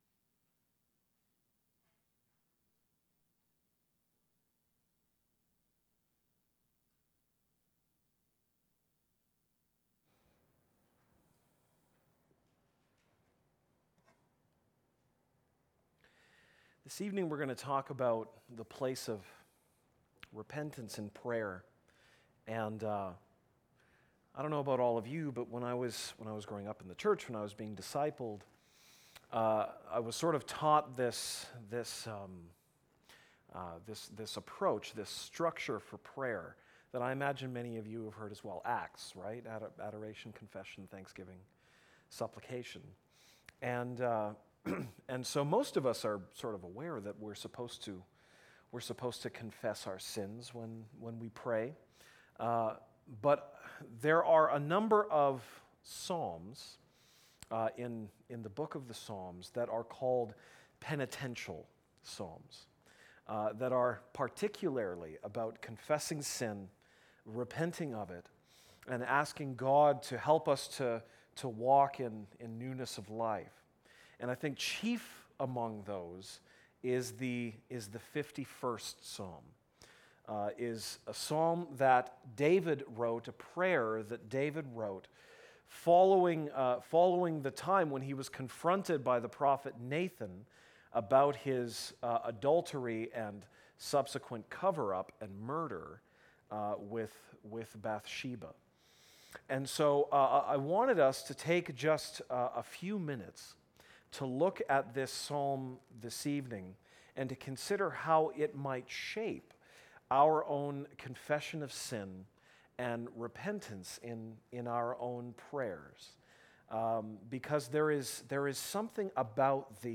Series: Evening service sermons 2017